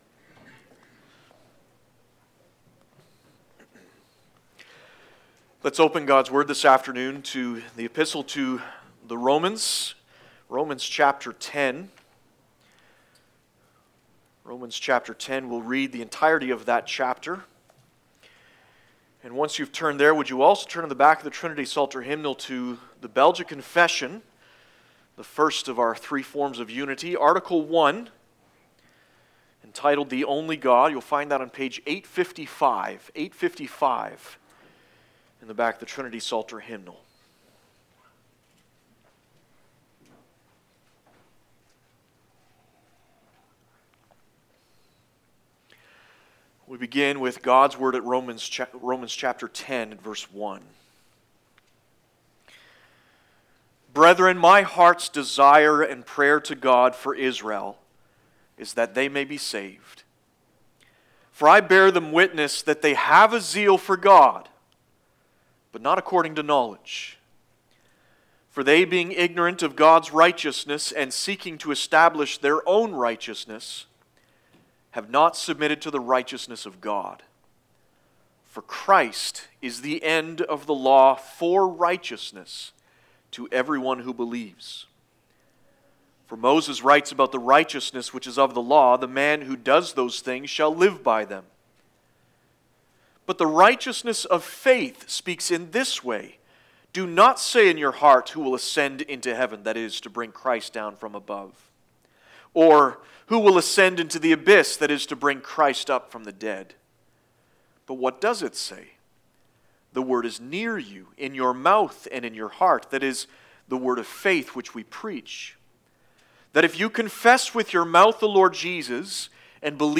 Passage: Romans 10 Service Type: Sunday Afternoon 1.